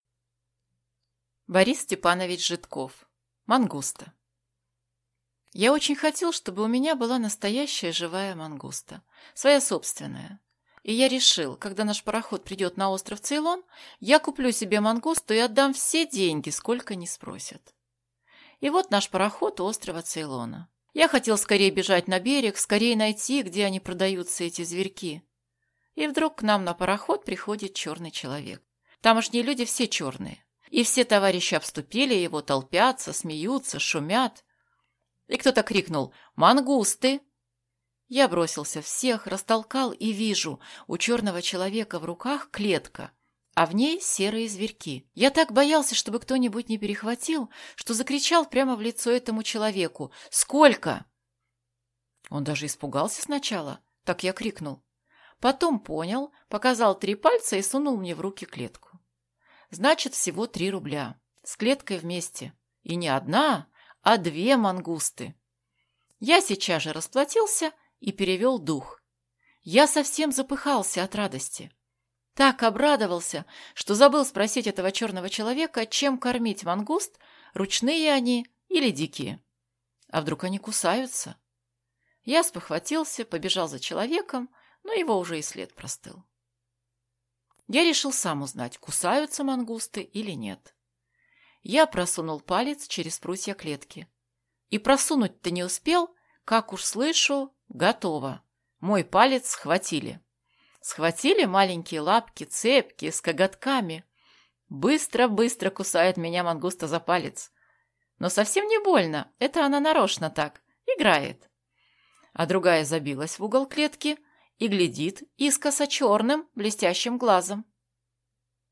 Аудиокнига Мангуста | Библиотека аудиокниг